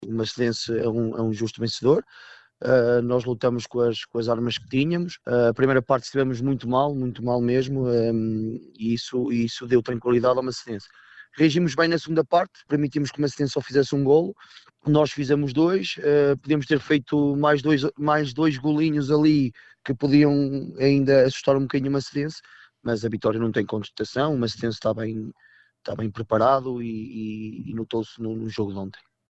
GDM-versus-Pedras-Salgadas-2.mp3